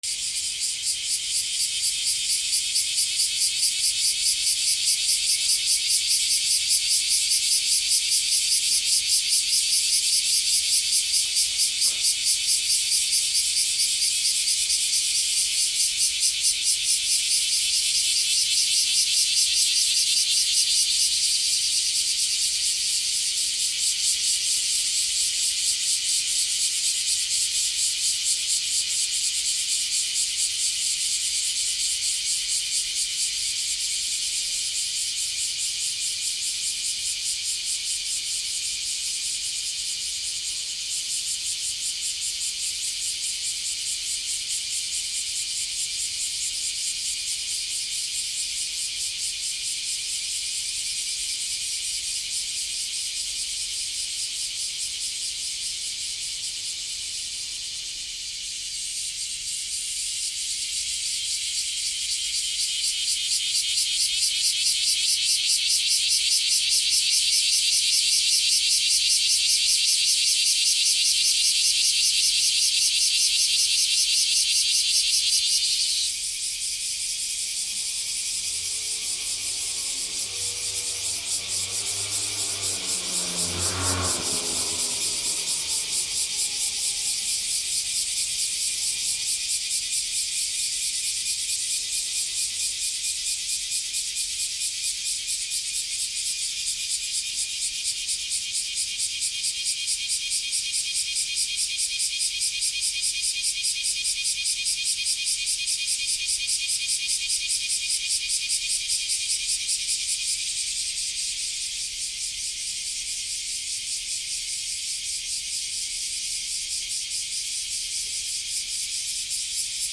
12 クマゼミ 多め
/ B｜環境音(自然) / B-25 ｜セミの鳴き声 / セミの鳴き声_20_クマゼミ